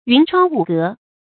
云窗霧閣 注音： ㄧㄨㄣˊ ㄔㄨㄤ ㄨˋ ㄍㄜˊ 讀音讀法： 意思解釋： 為云霧繚繞的窗戶和居室。